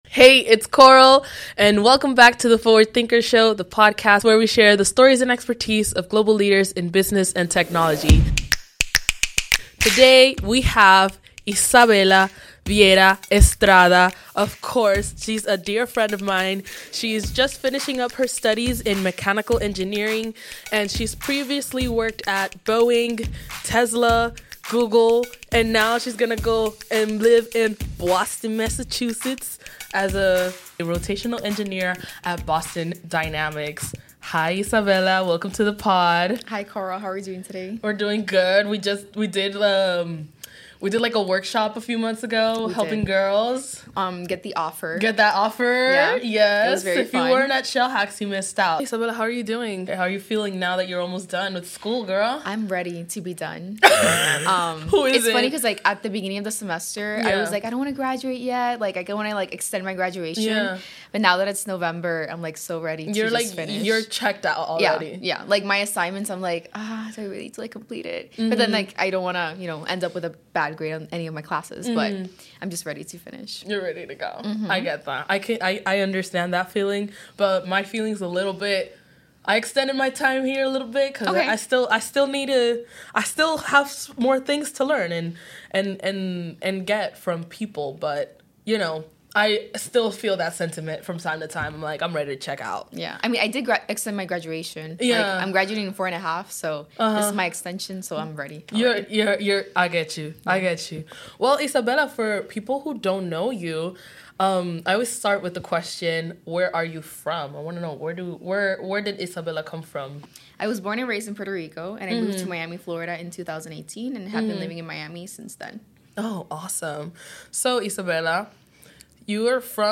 Featuring conversations with forward-thinking leaders from diverse backgrounds, the podcast captures unique insights and groundbreaking ideas.